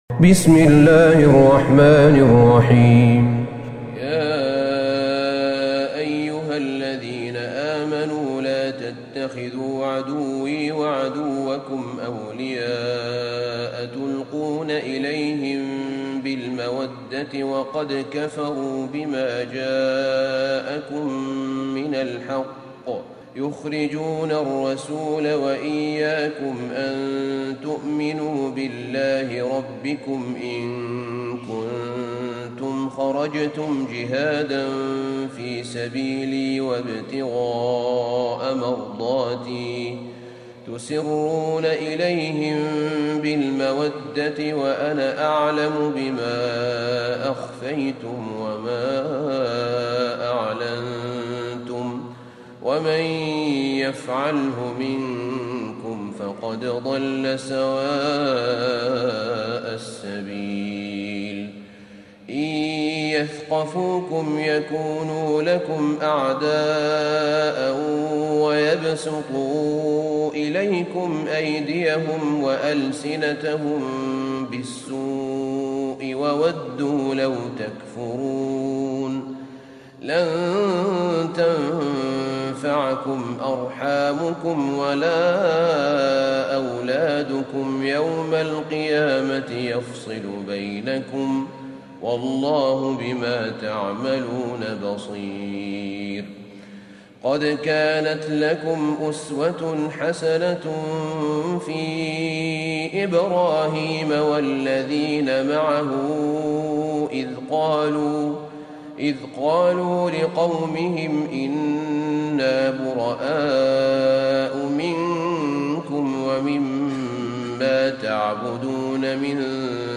سورة الممتحنة Surat Al-Mumtahanah > مصحف الشيخ أحمد بن طالب بن حميد من الحرم النبوي > المصحف - تلاوات الحرمين